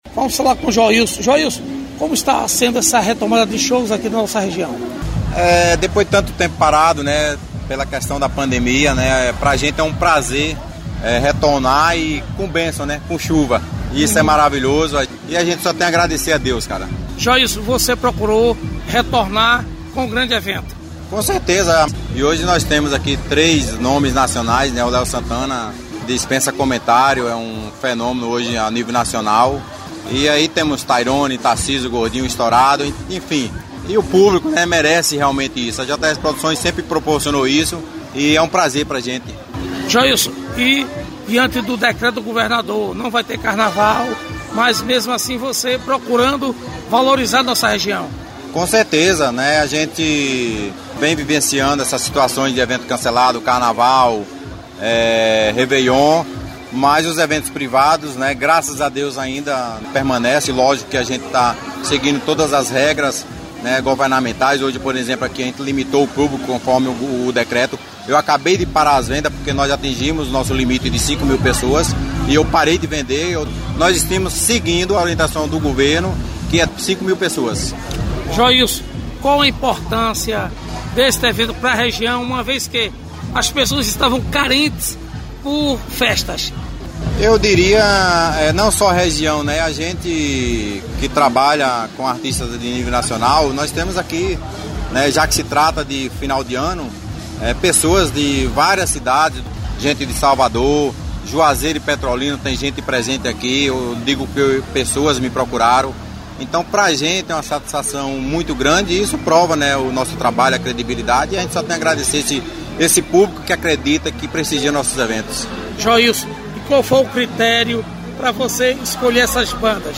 Reportagem: Cantores – Produtor de eventos, Léo Santana e Tayrone